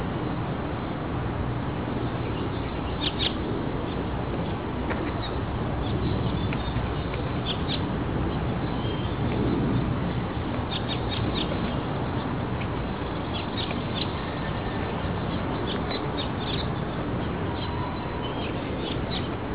amb_nature01.wav